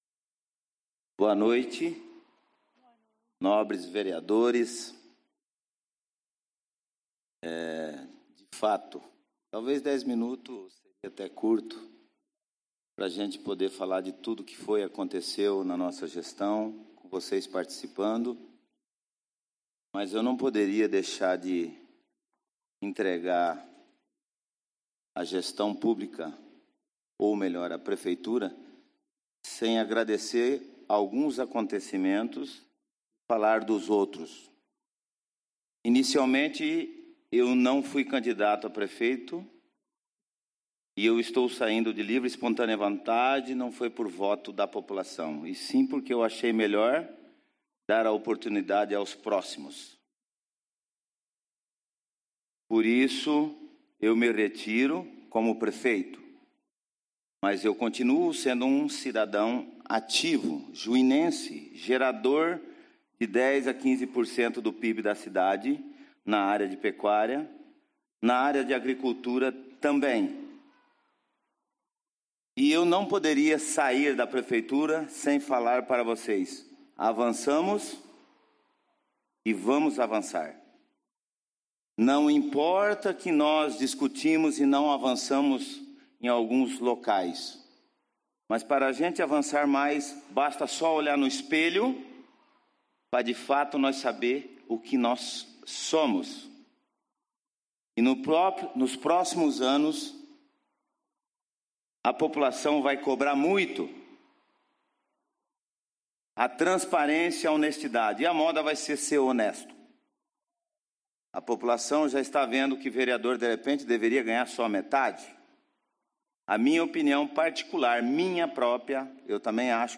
Tribuna do Povo utilizada pelo Prefeito Municipal de Juína, Hermes Lourenço Bergamin na Sessão Ordinária realizada no dia 12/12/2016 as 20 horas no Plenário Henrique Simionatto.